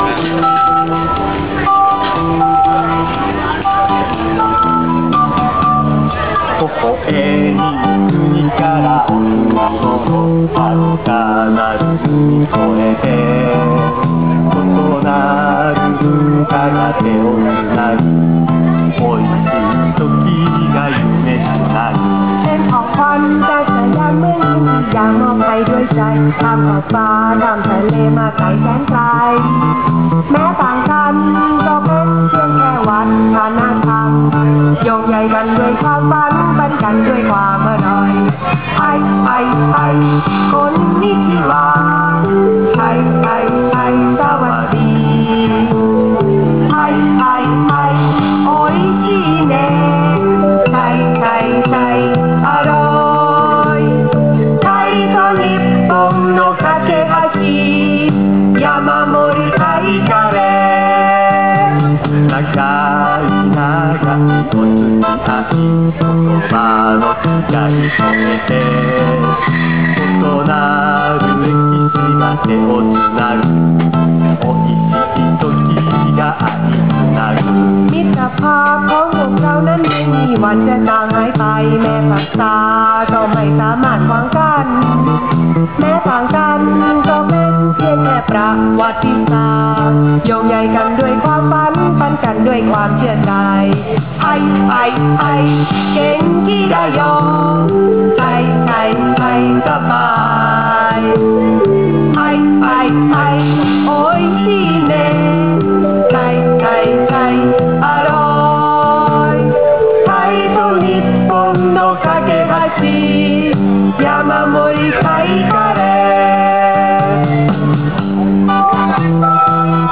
カレーを販売してるブースから流れる歌がバカバカしくて思わず録音してしまった。